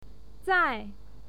zai4.mp3